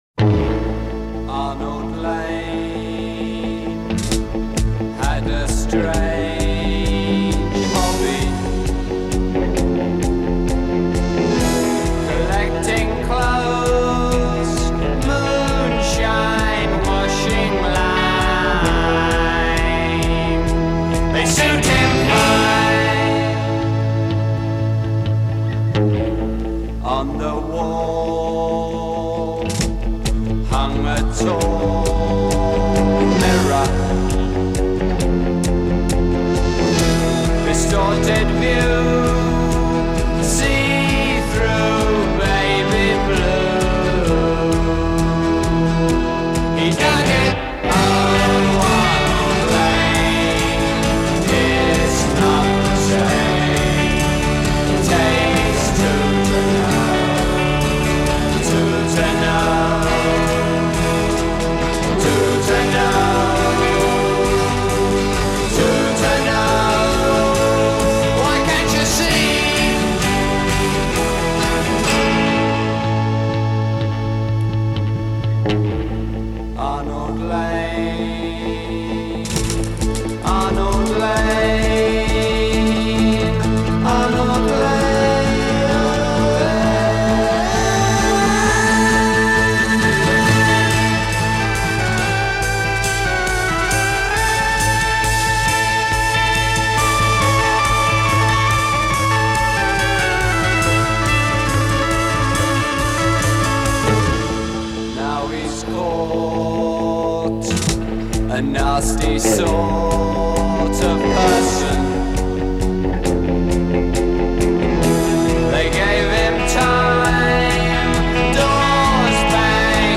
Psychedelic Rock, Progressive Rock